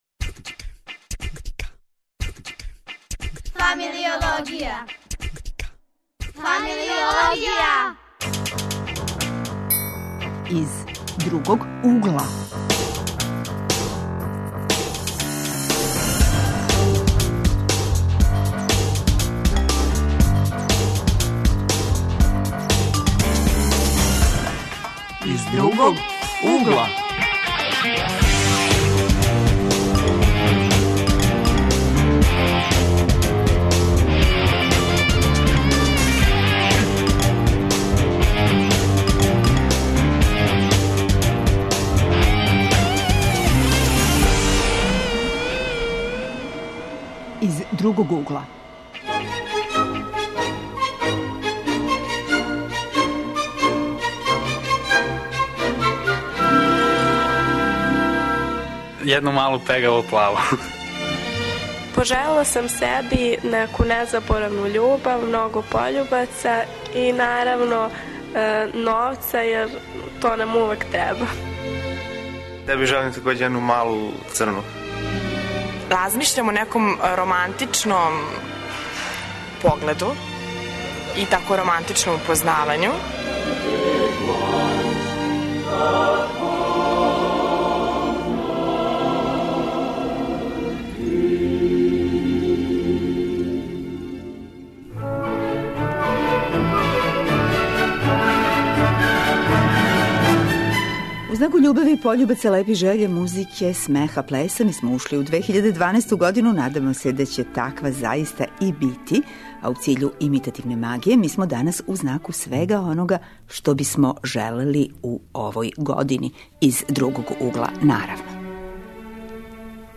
Гости су нам средњошколци и студенти, а наша радио енциклопедија бави се поменутим темама из забавно-фактографског угла.